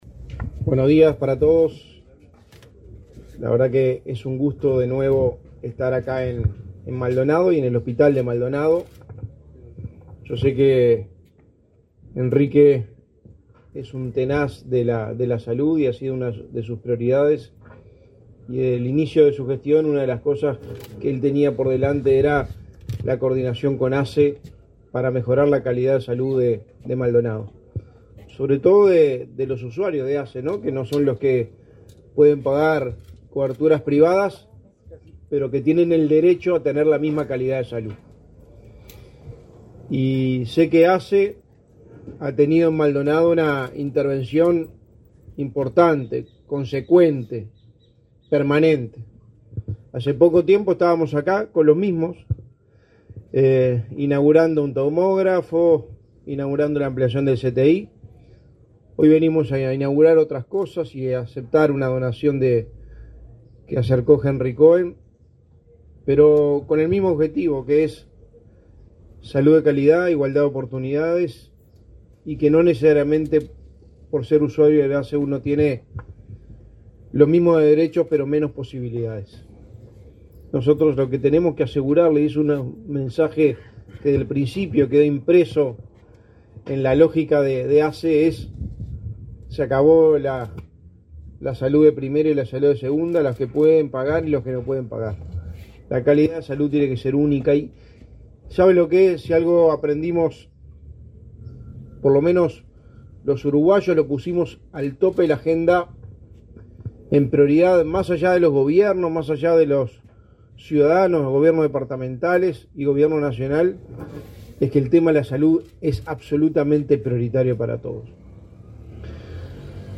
Palabras del secretario de Presidencia, Álvaro Delgado
Este 27 de noviembre, la Administración de los Servicios de Salud del Estado inauguró obras en el hospital de Maldonado.